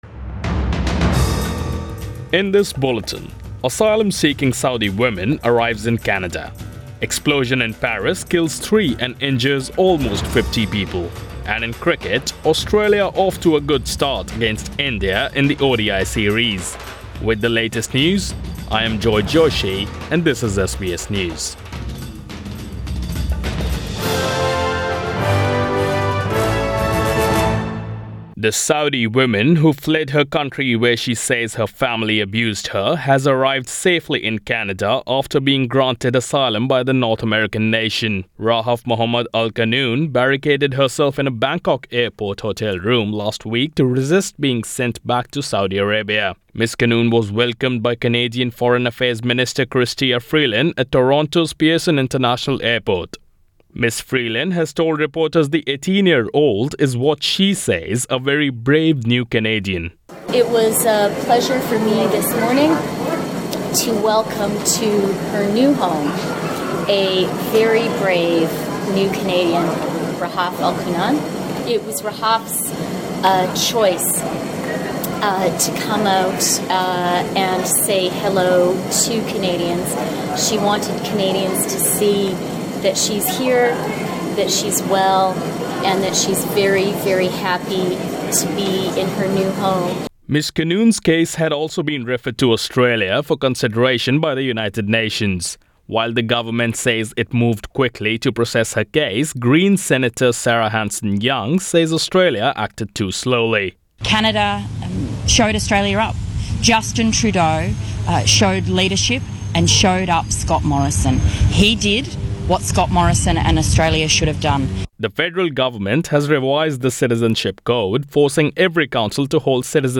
AM Bulletin 13 January